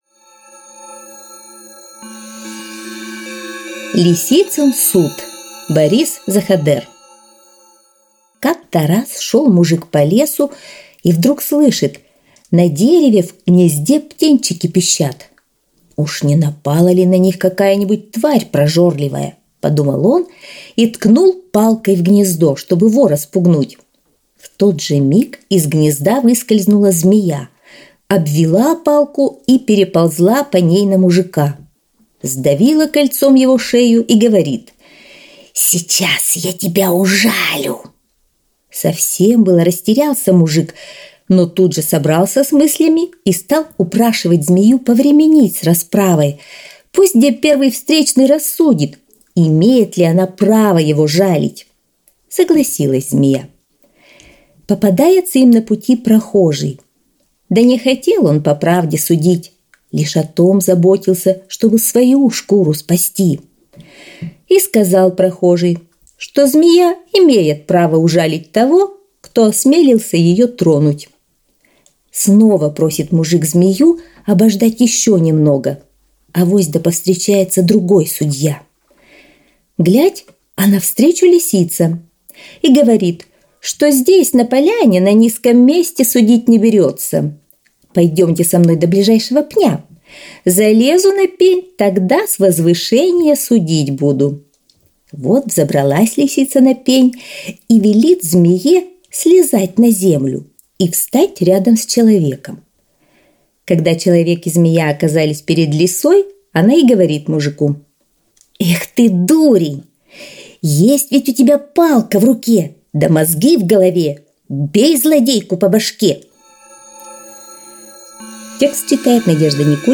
Лисицын суд - аудиосказка Заходера - слушать онлайн | Мишкины книжки
Лисицын суд – Заходер Б.В. (аудиоверсия)